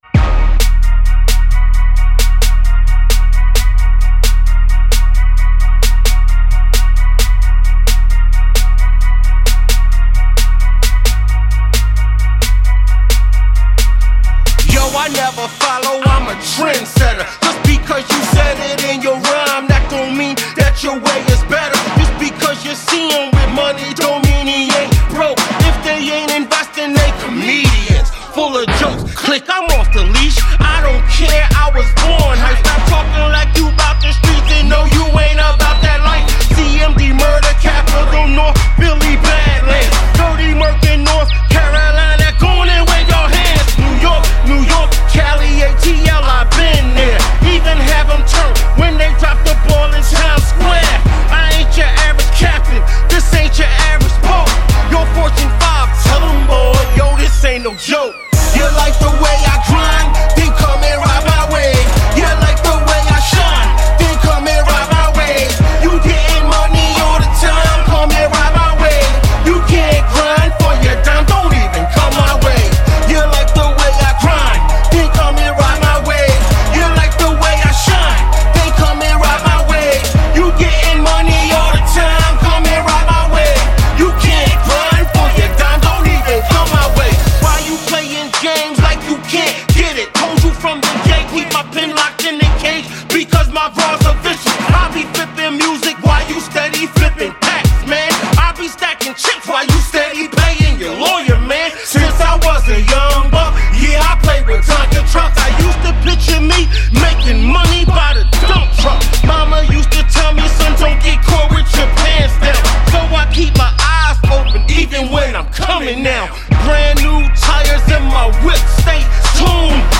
Hiphop
Description : EXCITING FULL OF ENERGY